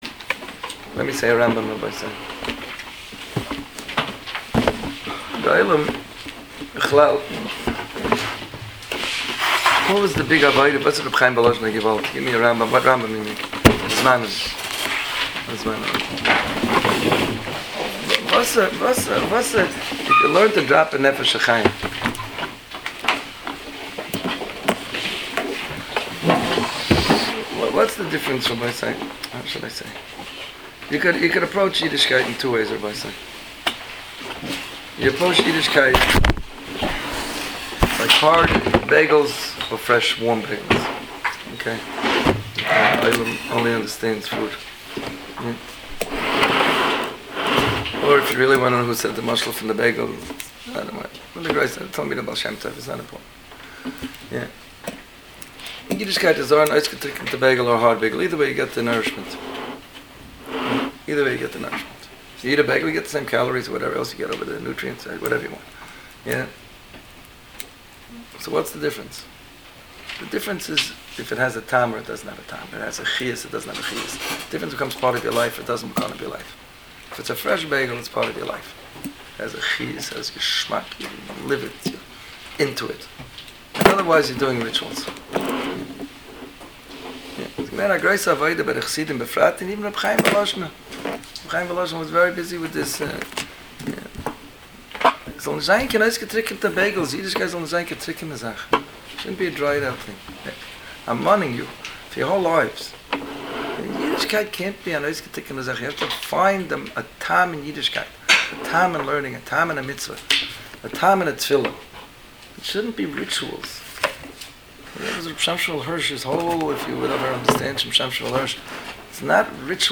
Chanuka at Yeshiva – מסיבת חנוכה בקליפווד תשס״ב/2011